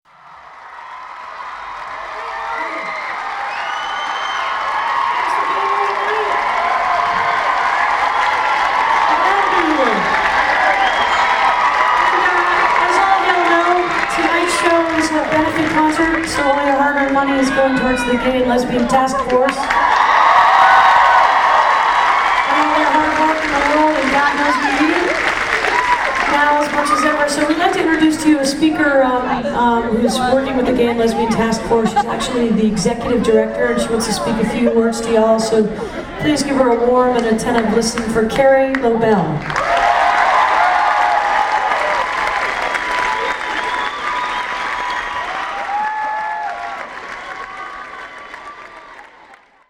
lifeblood: bootlegs: 1998-08-10: 9:30 club - washington, d.c. (gay and lesbian task force benefit)